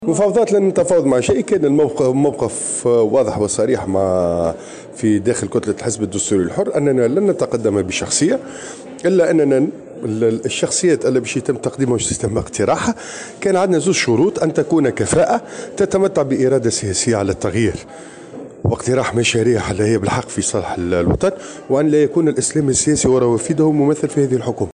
قال النائب عن الدستوري الحر كريم كريفة في تصريح لمراسلة الجوهرة "اف ام" اليوم الخميس إن الموقف داخل كتلة الحزب بالبرلمان كان واضحا وصريحا وهو عدم تقديم أي مرشح لمنصب رئيس الحكومة .